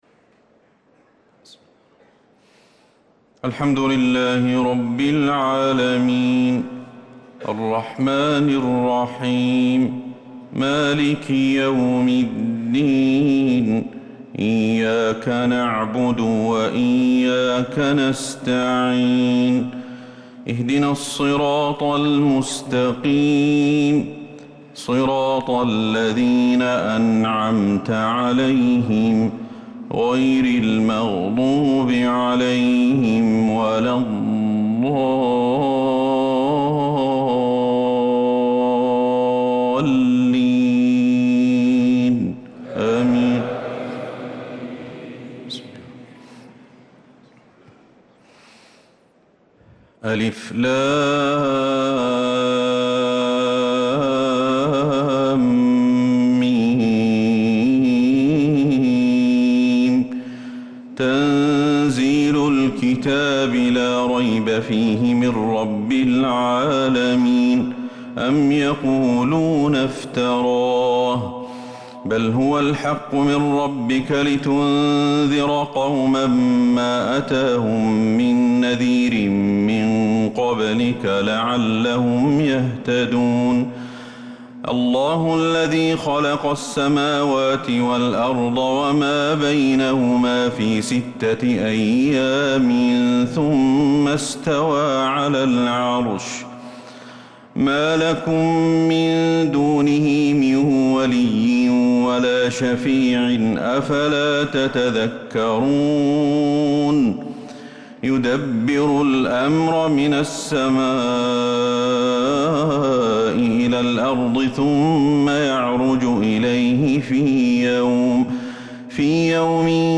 صلاة الفجر للقارئ أحمد الحذيفي 10 شوال 1445 هـ
تِلَاوَات الْحَرَمَيْن .